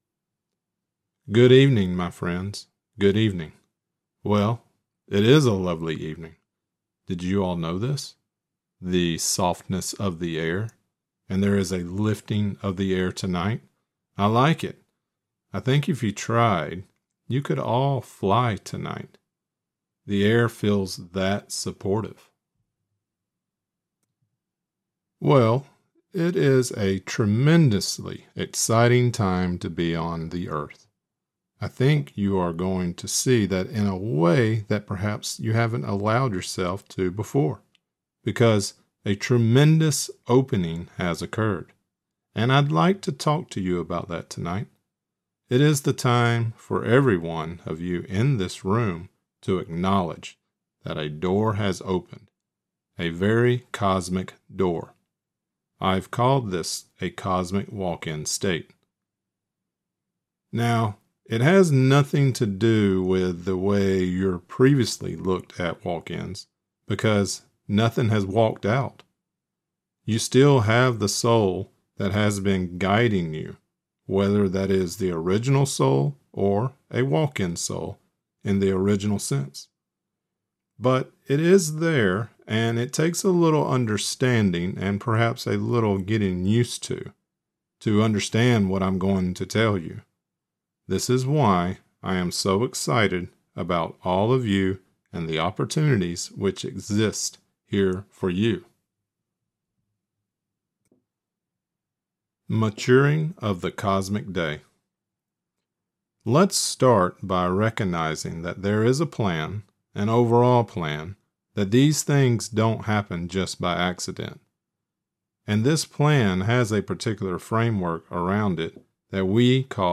This lecture includes the channeling of Vywamus: * Maturing of the Cosmic Day.